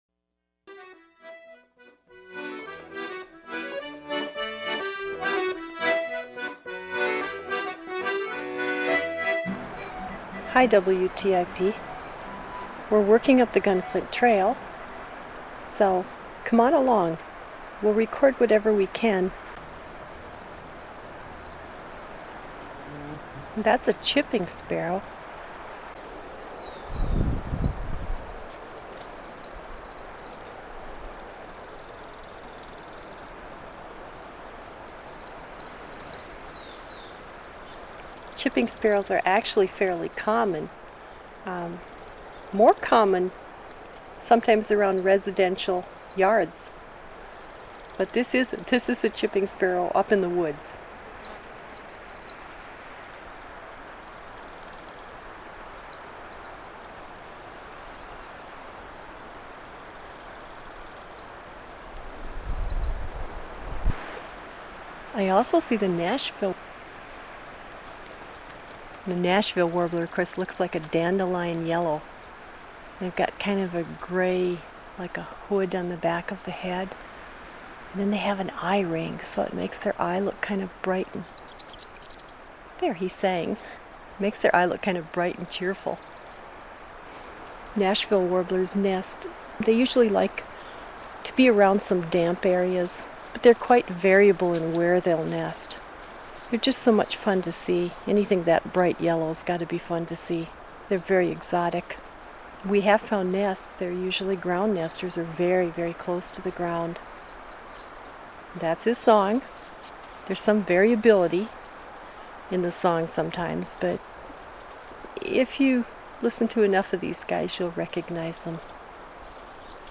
Recorded in the fields and woodlands of Northeastern Minnesota